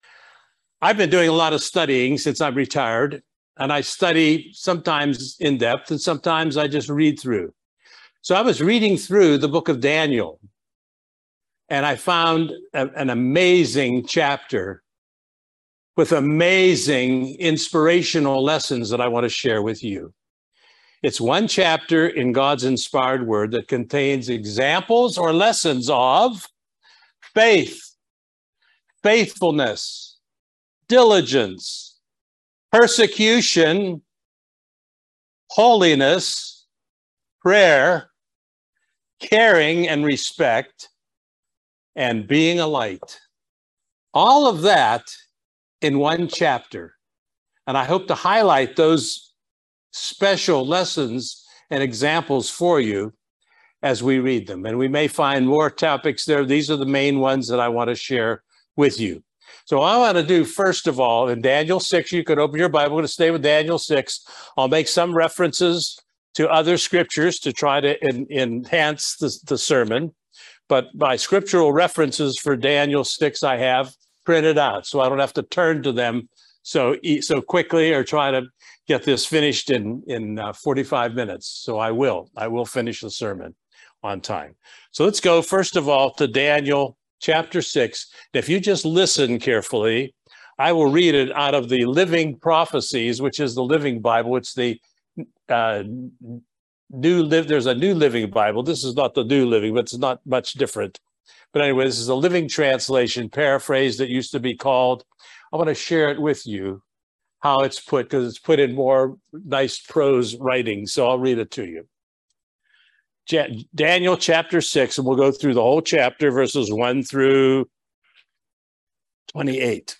Join us for this enlightening video Sermon on the subject of Daniel Chapter 6.